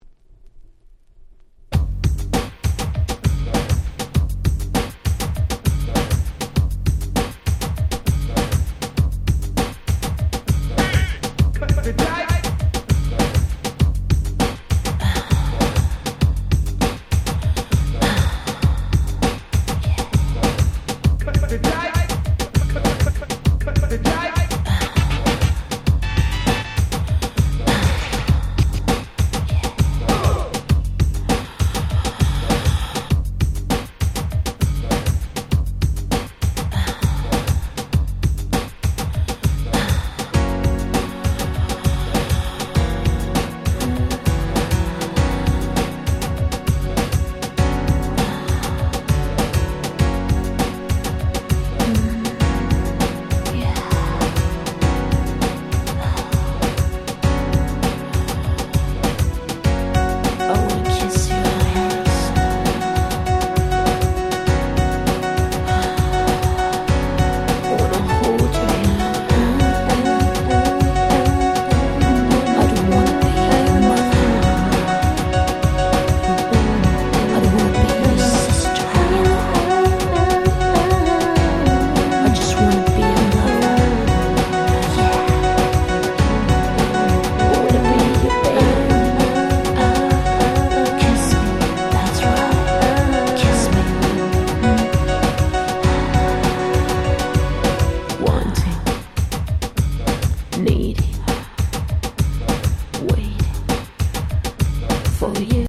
90' Nice Ground Beat !!